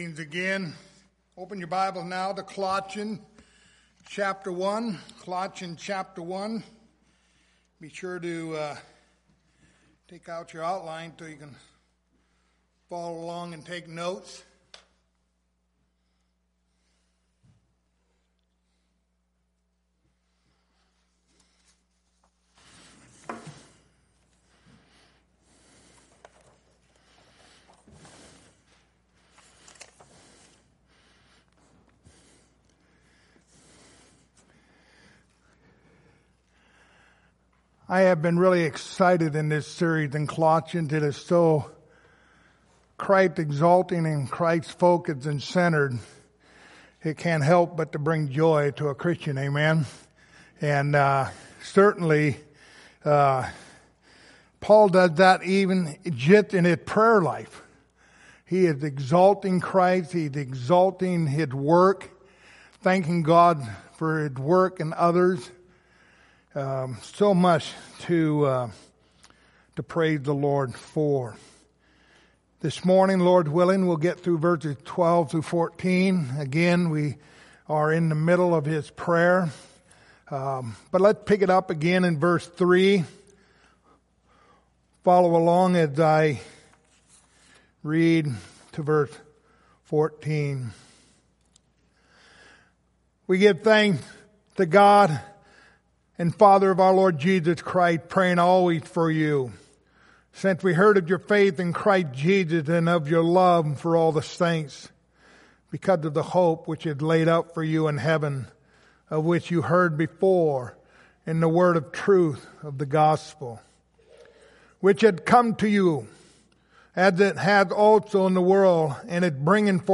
Passage: Colossians 1:12-14 Service Type: Sunday Morning